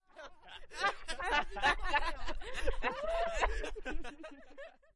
嘲笑Wahwah效果。它会产生不同的感觉。令人不快......正常化。